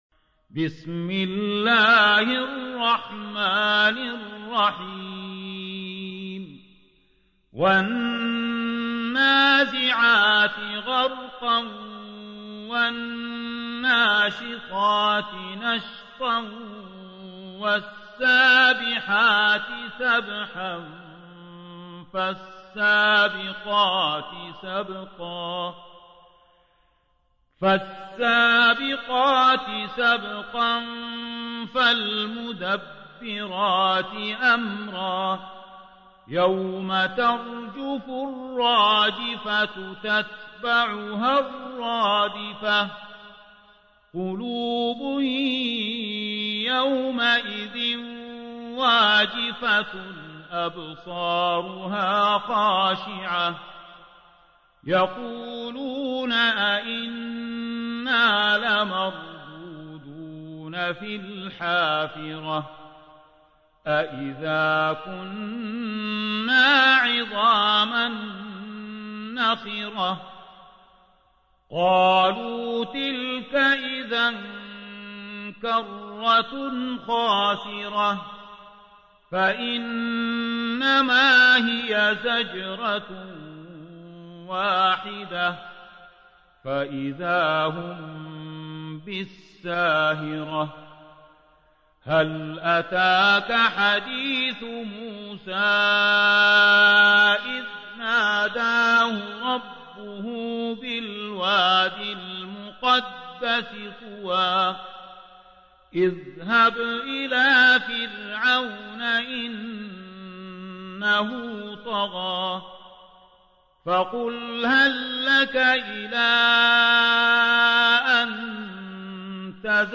79. سورة النازعات / القارئ